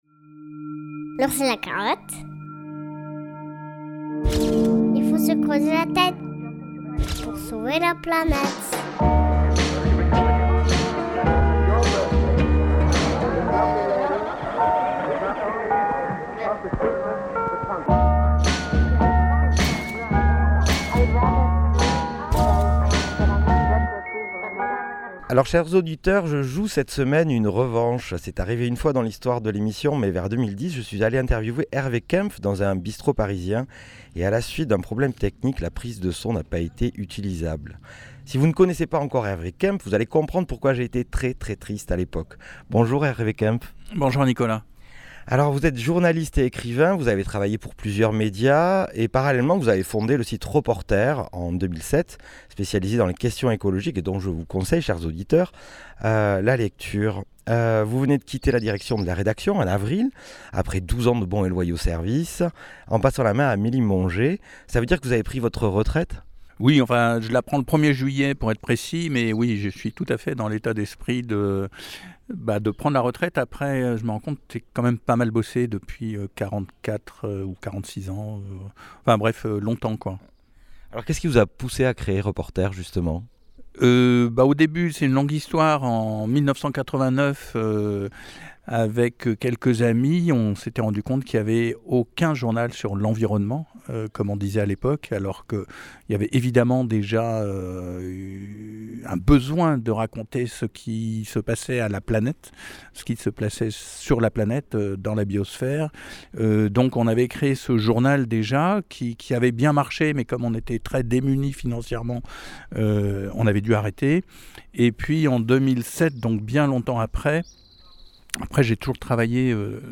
C'est aussi un essayiste qui ne cesse de dénoncer les liens entre la destruction de notre biosphère et la dérive actuelle du capitalisme. Une interview passionnante qui vous est proposée exceptionnellement en deux épisodes pour ne pas en perdre une miette.